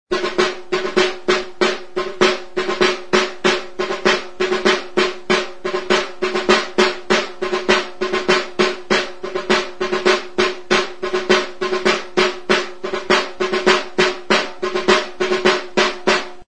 Membranófonos -> Golpeados -> Tambores con palos
Zurezko kaxa zilindrikoa du. Larruzko bi mintz ditu. Tenkatzeko sokazko sistema du. Larruzko tensoreak ditu. Atzeko mintzean bordoia du. Besotik zintzilikatzeko soka du. Skay beltzezko funda dauka. Batiente den larrua hautsia dago.